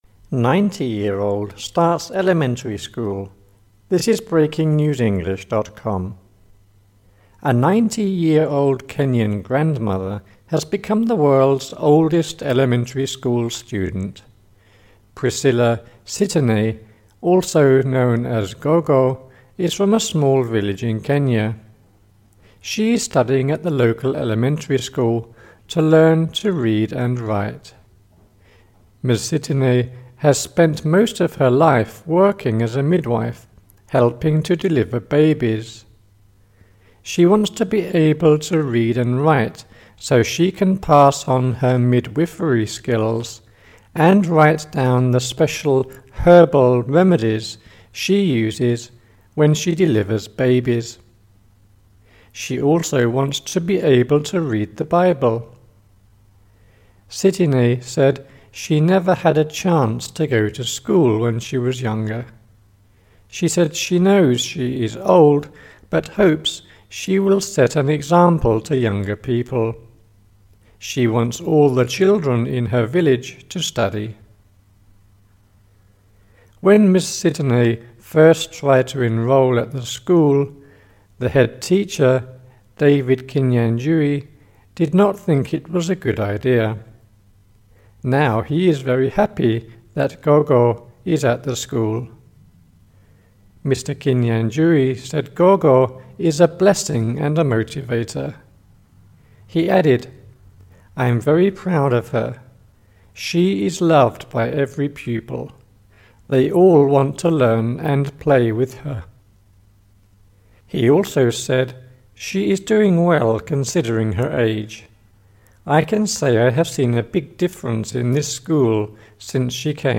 British speaker